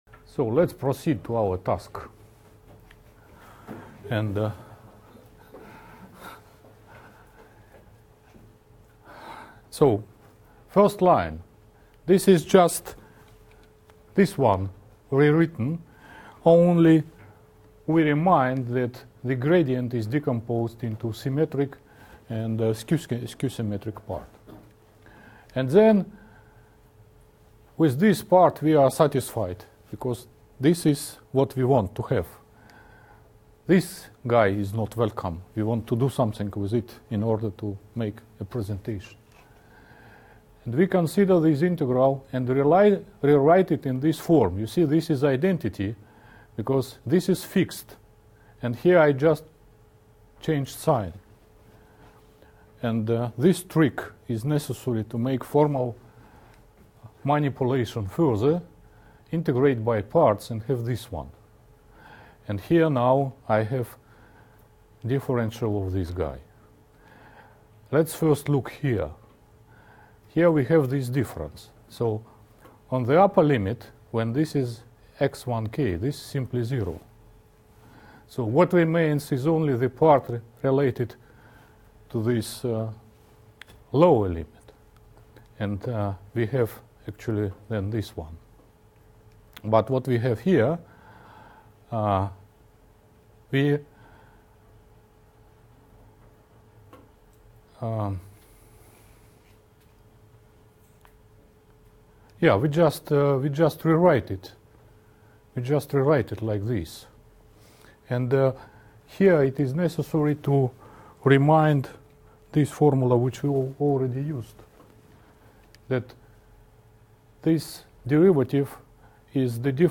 lecture series on mathematical theory of plasticity